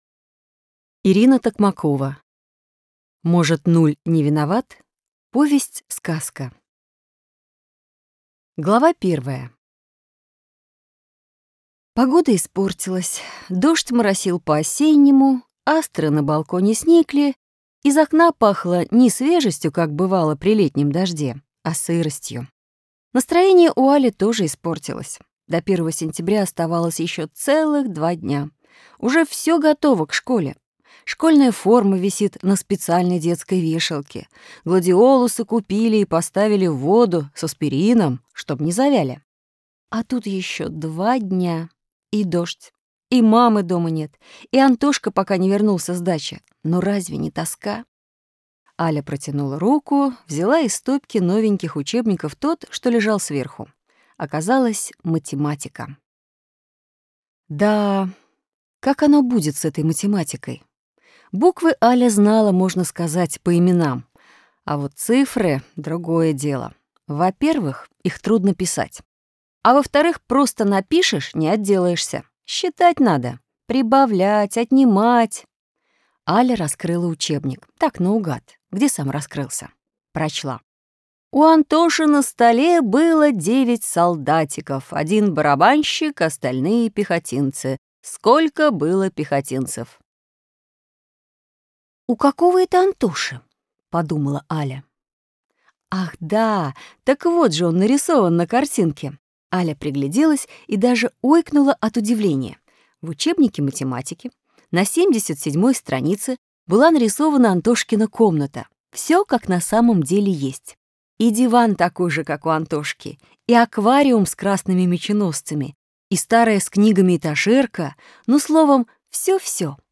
Аудиокнига Может, нуль не виноват?